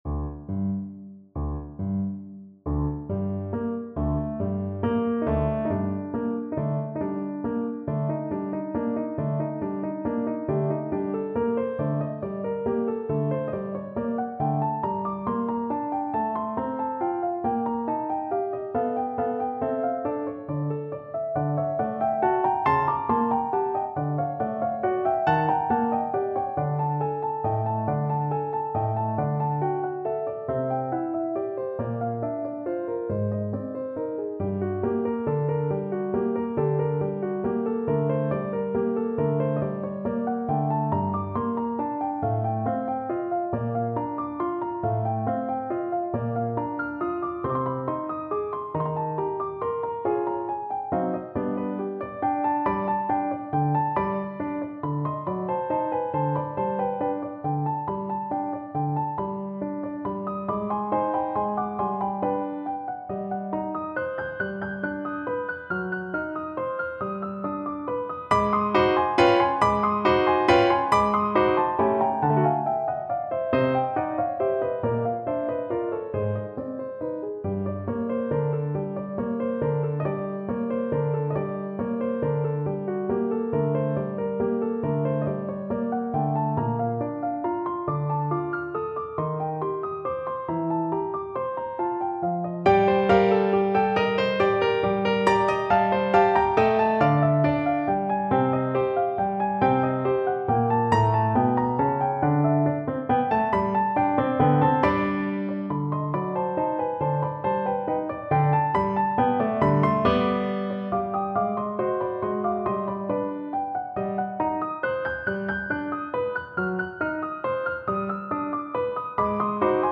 Clarinet version
Classical Clarinet
Piano Playalong MP3